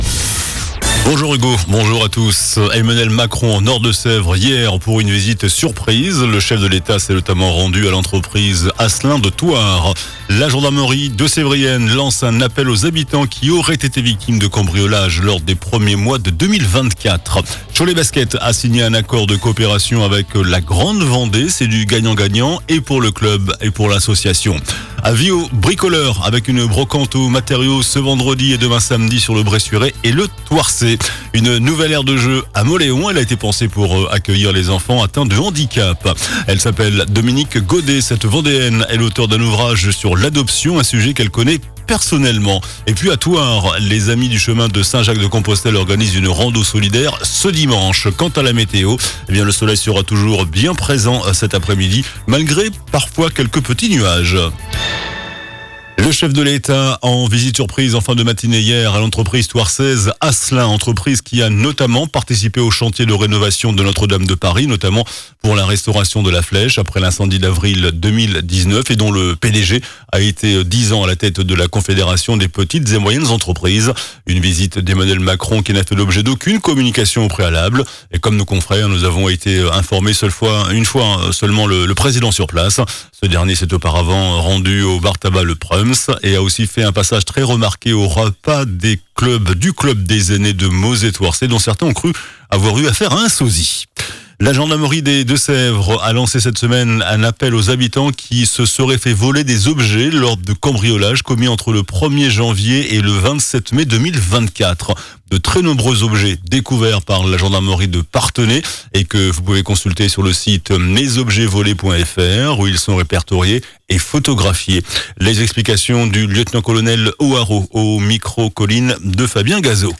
JOURNAL DU VENDREDI 11 AVRIL ( MIDI )